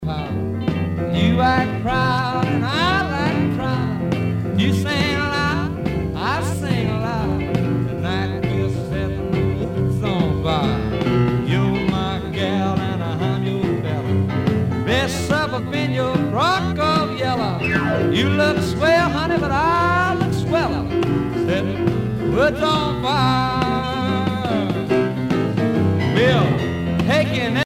danse : rock
Pièce musicale éditée